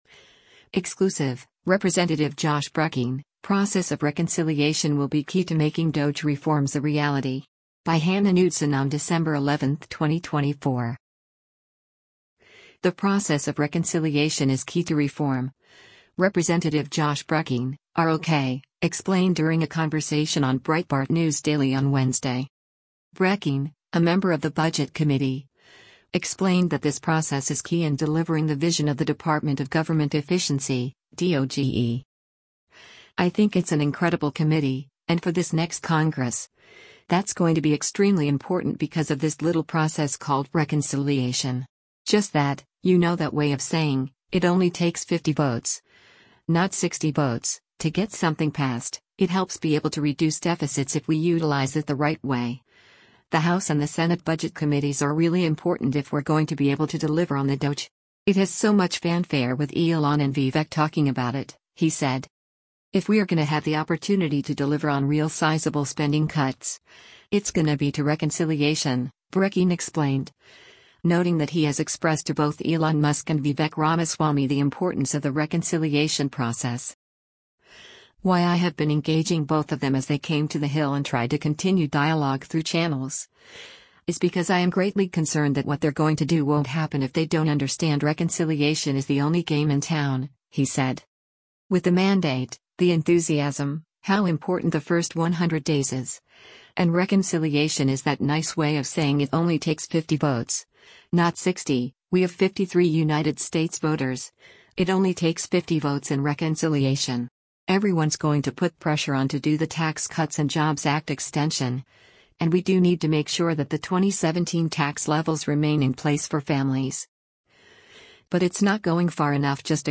The process of reconciliation is key to reform, Rep. Josh Brecheen (R-OK) explained during a conversation on Breitbart News Daily on Wednesday.